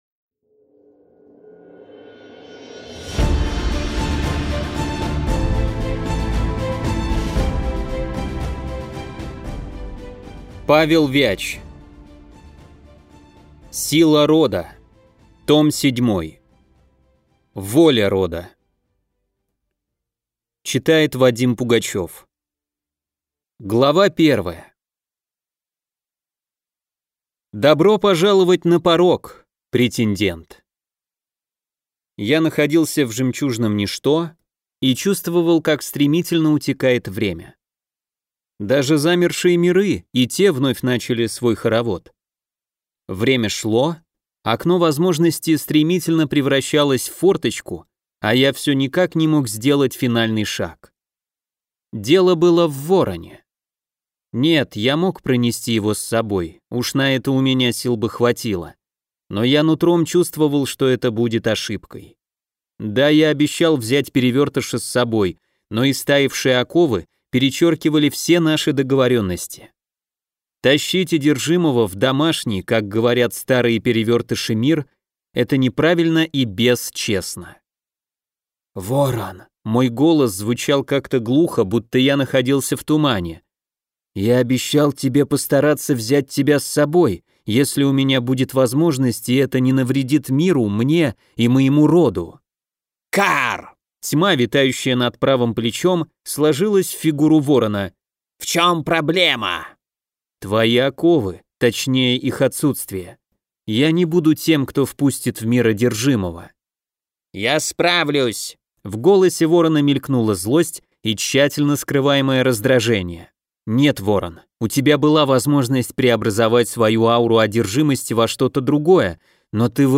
Aудиокнига Воля рода
Читает аудиокнигу